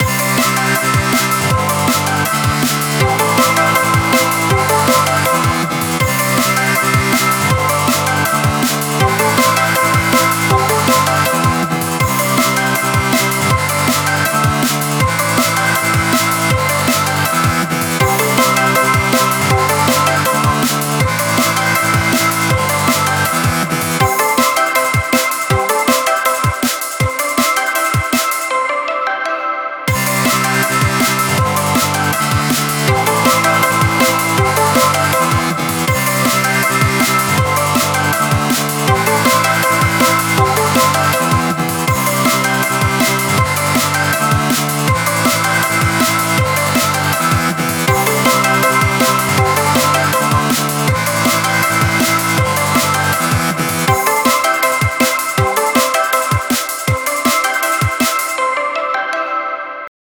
וואוו יפה מאוד רק זה לא מתפתח למשהו מעניין לדוגמא להתחיל ברגוע יחסית ואז את הבועט הבנת?
זה לא נכון לעשות צילצול כרצועת שמע מלאה עם המון בסים, כי אתה לא תשמע את זה דרך הטלפון…
אני מבין את מה שאתה אומר ולכן גם בחרתי בס עם דיסטורשן כזה כדי שישמעו אותו בטלפון וכמעט ואין לו נמוכים לפחות ככה זה נשמע אצלי במוניטורים